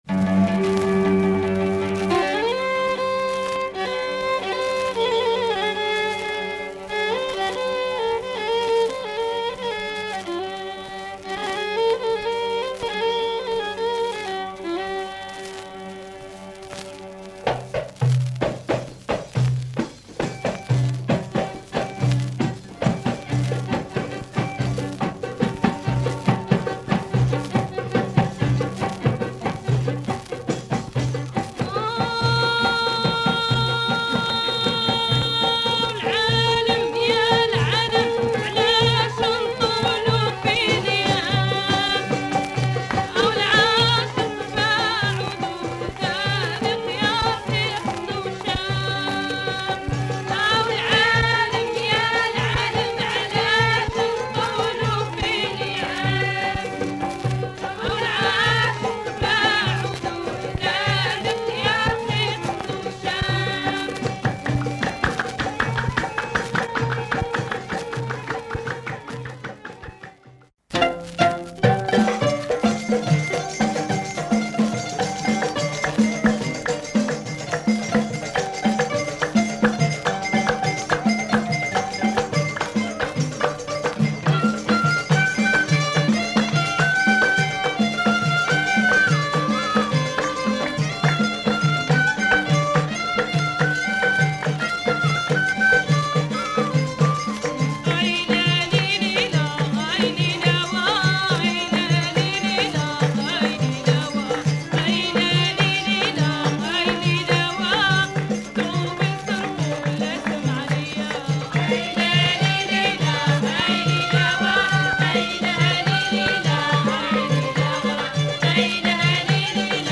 Rare Algerian 7'
Superb music and chant, superb cover art.